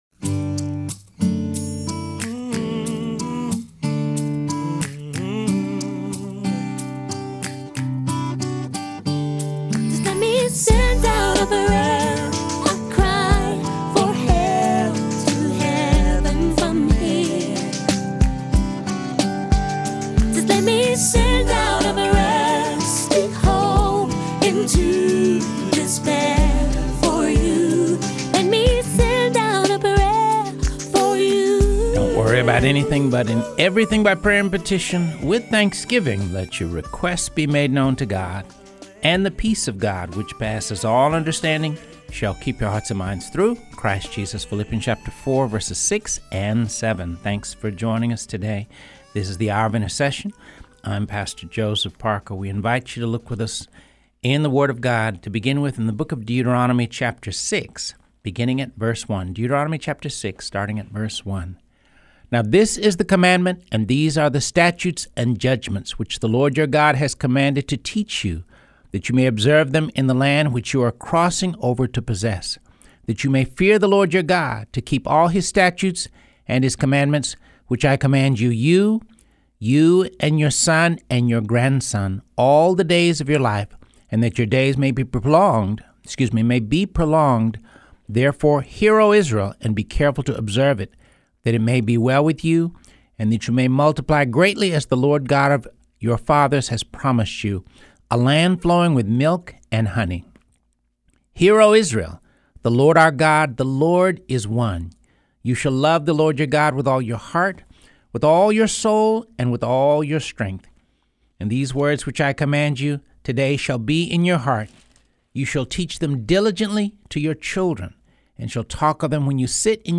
two young men from her community